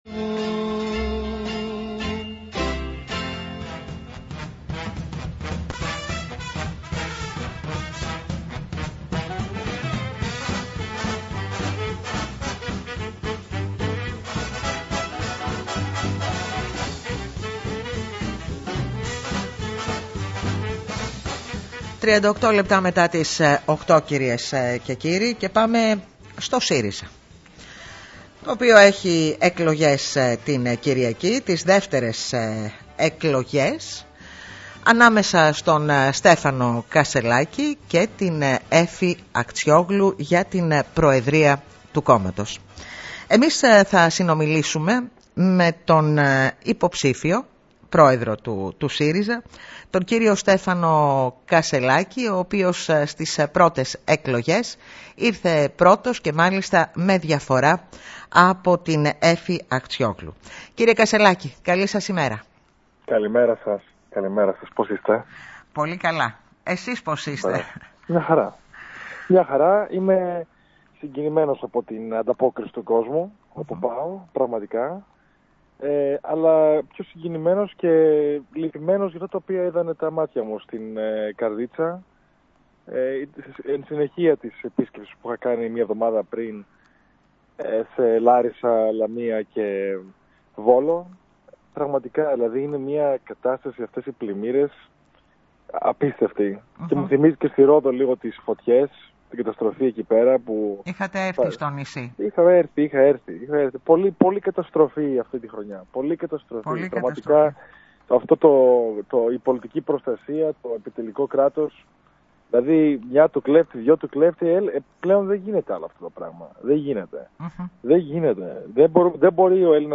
Αυτό δήλωσε μιλώντας στην ΕΡΤ Νοτίου Αιγαίου ο υποψήφιος για την προεδρία του ΣΥΡΙΖΑ-Προοδευτική Συμμαχία, Στέφανος Κασσελάκης, αναφερόμενος στην επόμενη ημέρα.